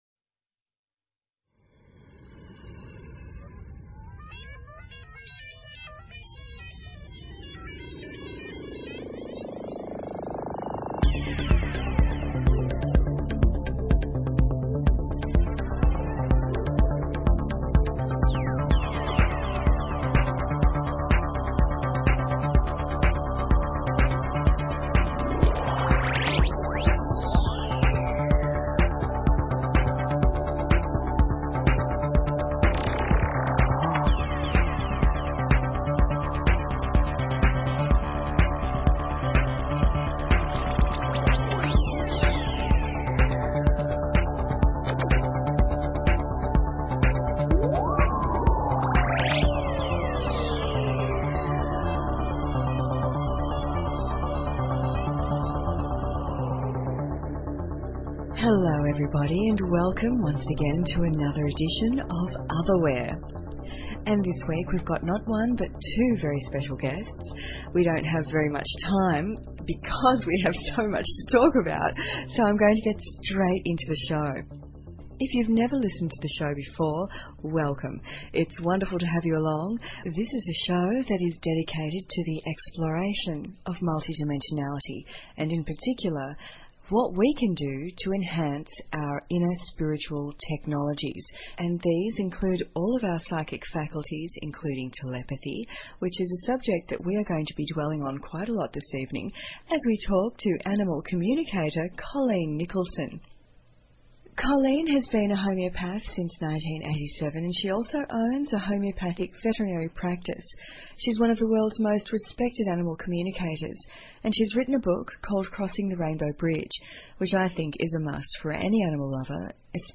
Talk Show Episode, Audio Podcast, Otherware and Courtesy of BBS Radio on , show guests , about , categorized as